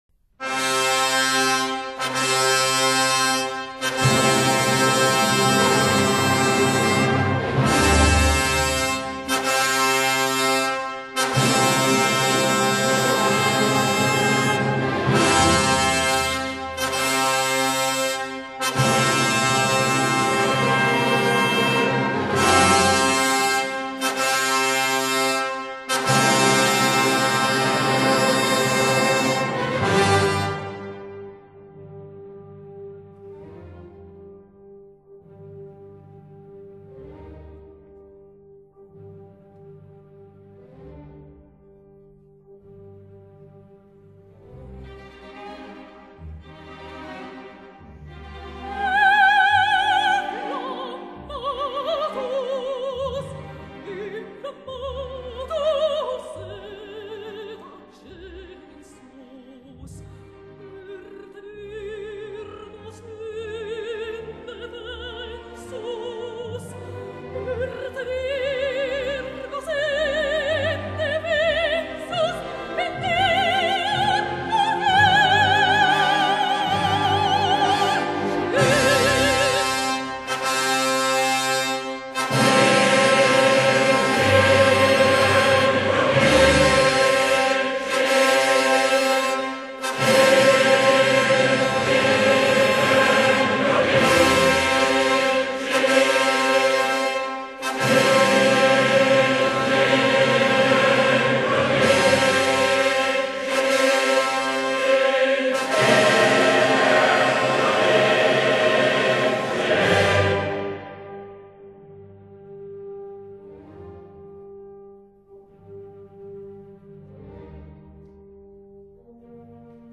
Aria (Soprano I) e Coro. Andante maestoso.